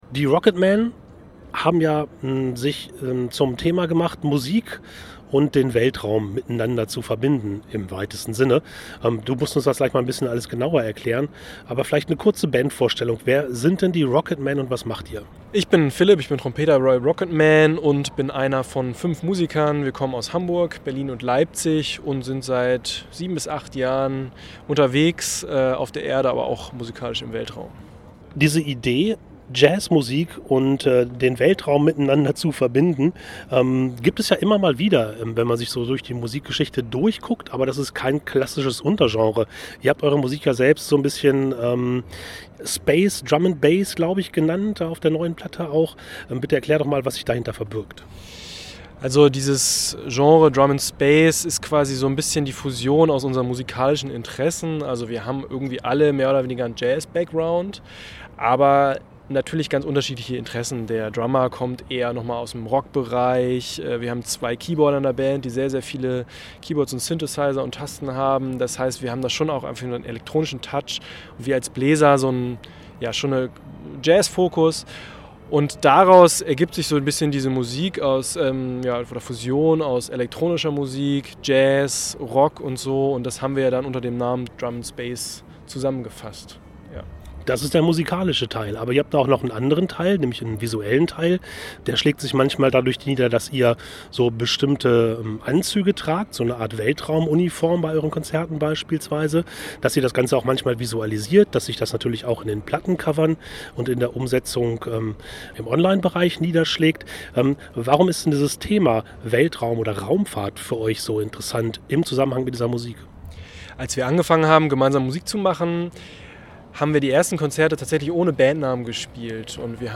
Radiointerview